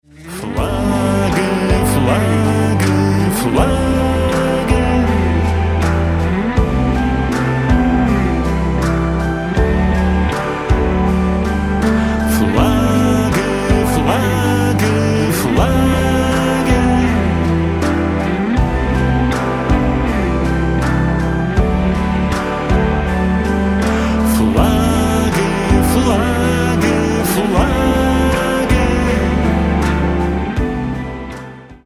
Рингтоны Альтернатива » # Спокойные И Тихие Рингтоны
Рок Металл Рингтоны